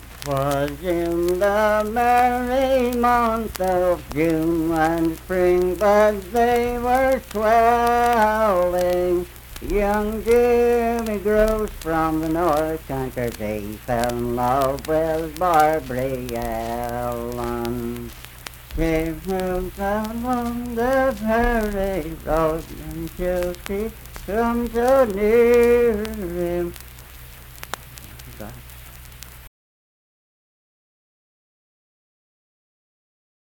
Unaccompanied vocal music performance
Verse-refrain 2(2-4).
Voice (sung)